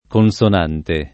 [ kon S on # nte ]